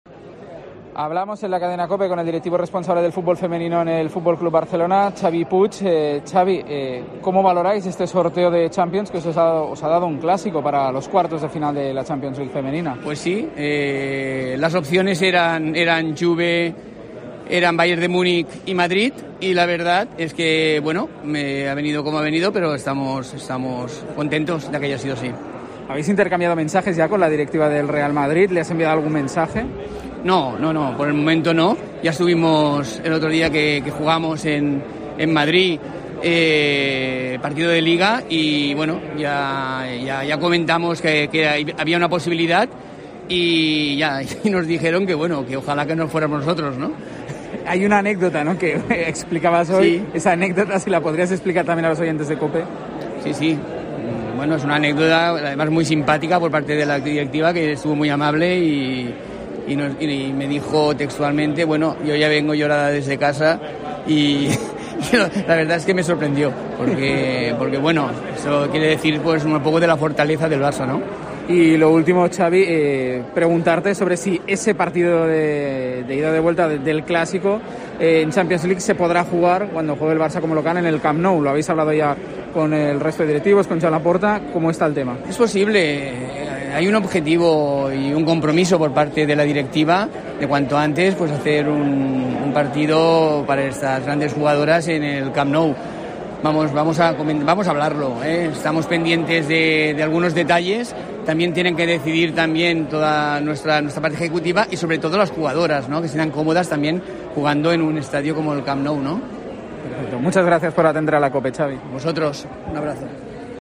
en la Gala de las Estrellas de la Federación Catalana de Fútbol